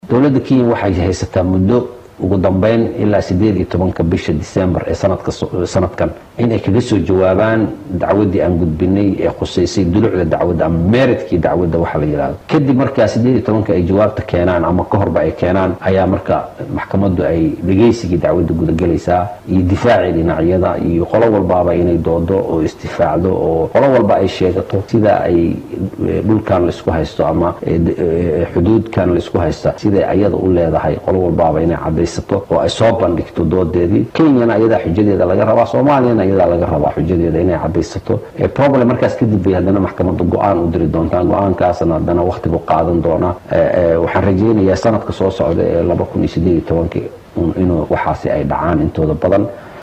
xeer-ilaaliyaha-qaranka-oo-ka-hadlaya-dacwada-badda.mp3